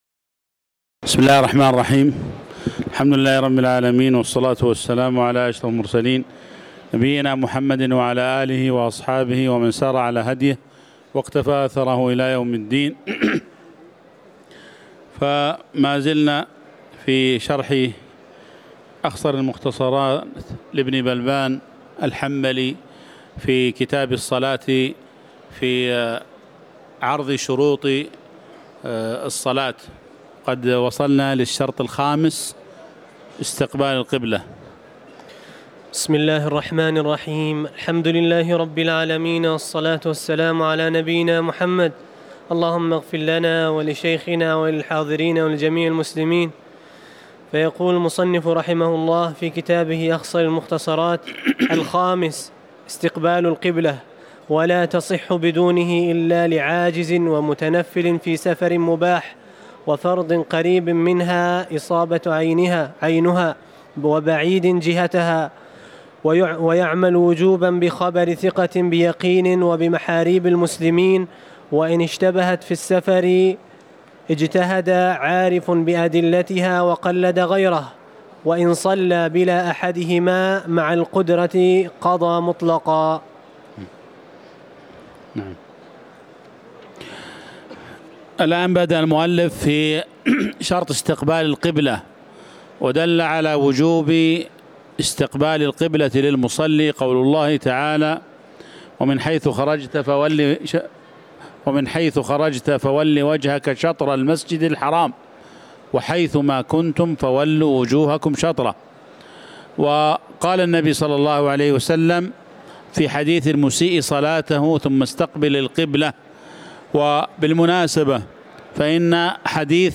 تاريخ النشر ١٥ ربيع الثاني ١٤٤١ هـ المكان: المسجد النبوي الشيخ